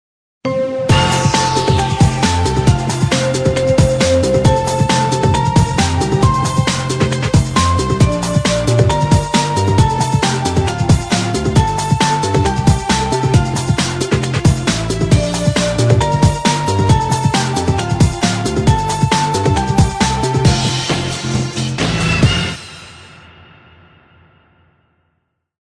Категория: Новогодние рингтоны (MP3)